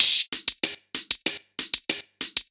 Index of /musicradar/ultimate-hihat-samples/95bpm
UHH_ElectroHatB_95-02.wav